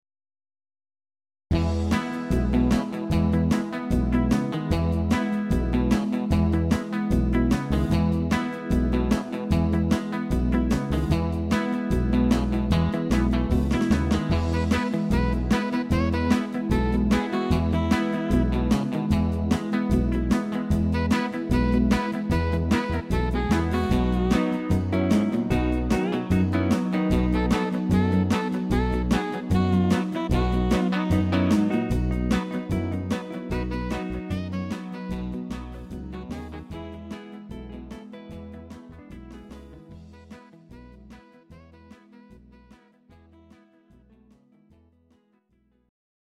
Rock Music